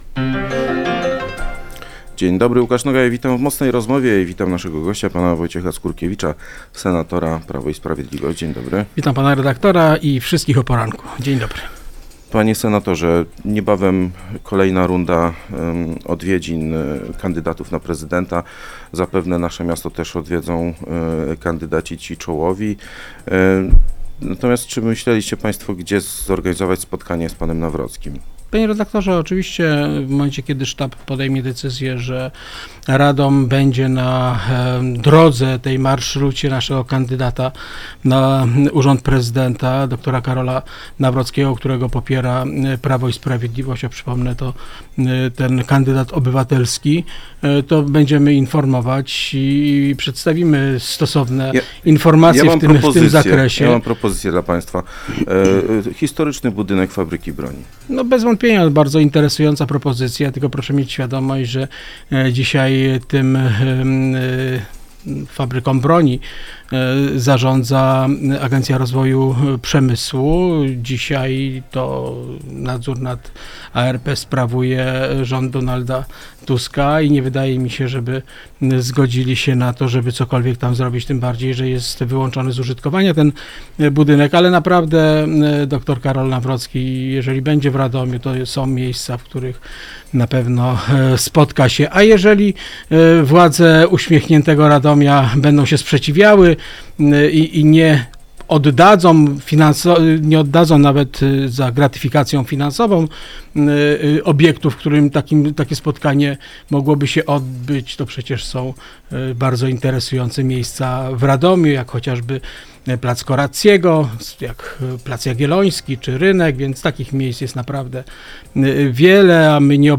Wojciech Skurkiewicz, senator Prawa i Sprawiedliwości był gościem